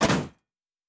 pgs/Assets/Audio/Fantasy Interface Sounds/Weapon UI 13.wav at 7452e70b8c5ad2f7daae623e1a952eb18c9caab4
Weapon UI 13.wav